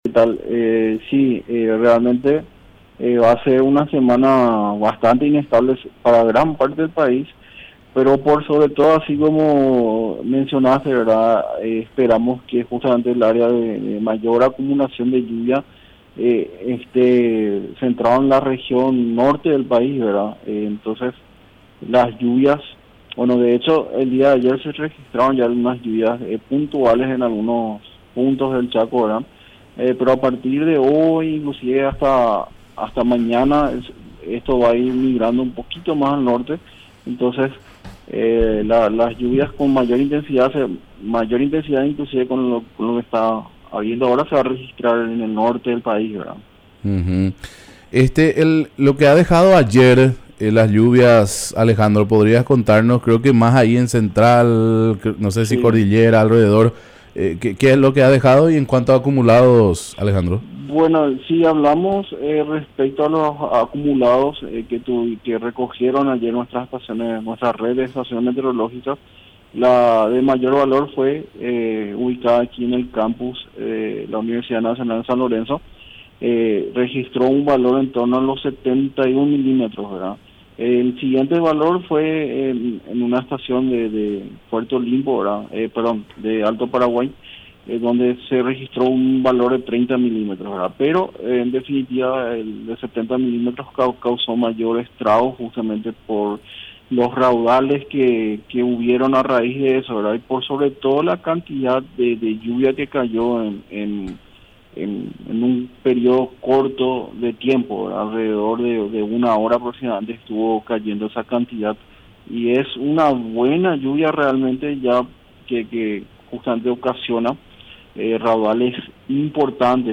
Reporte Meteorológico. 09/03/2026